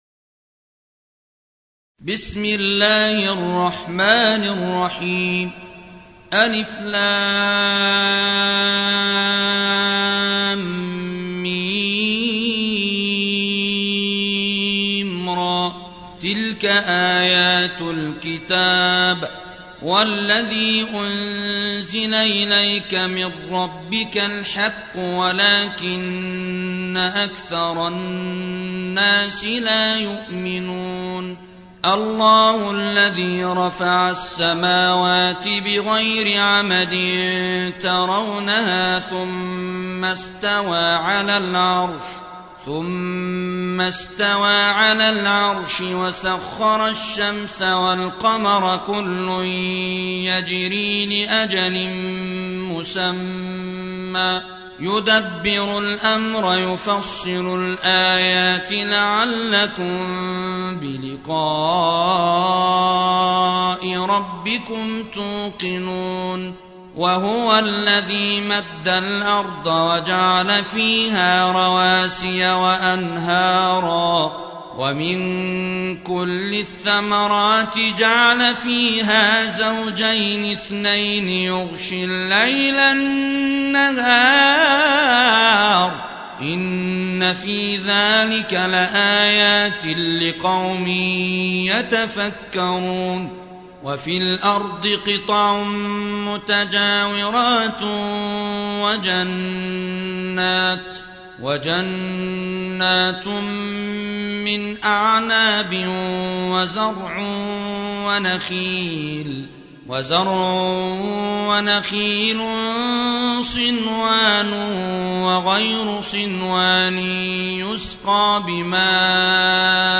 13. سورة الرعد / القارئ